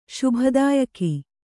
♪ śubha dāyaki